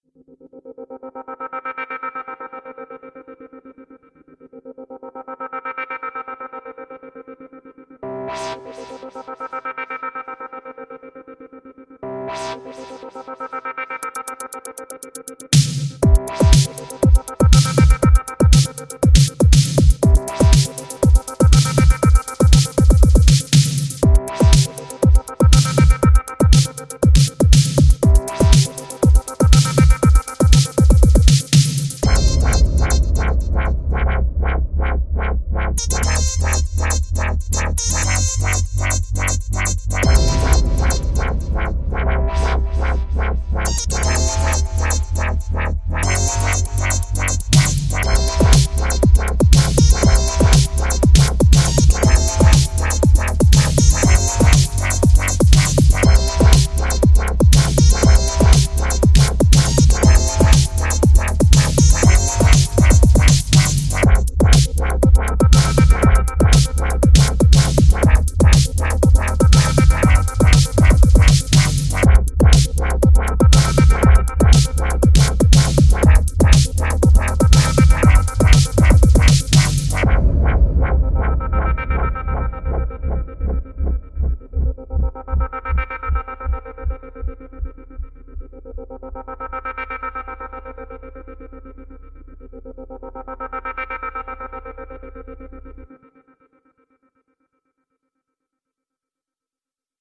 Here is a basic track I’ve built up purely using that one snare sample; every sound in the track is made from the sample, using the techniques outlined in this tutorial.